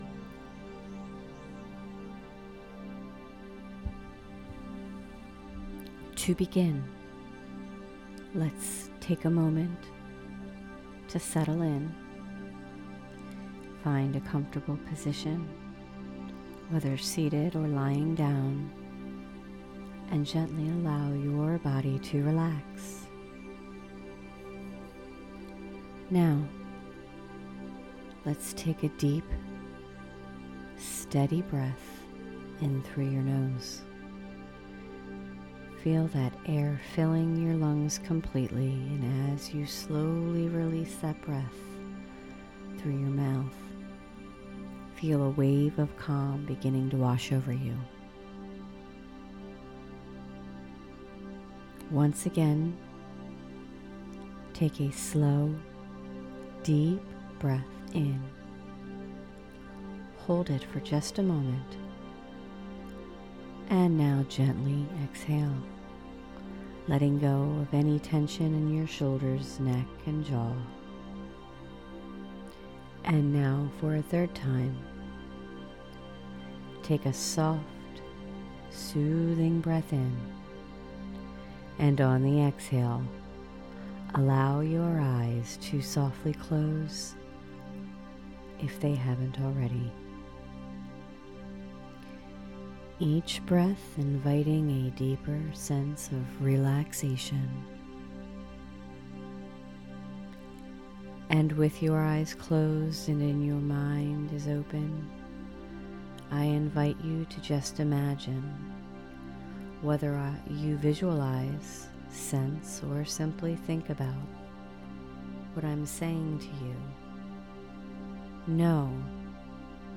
Beginner Guided Meditation.mp3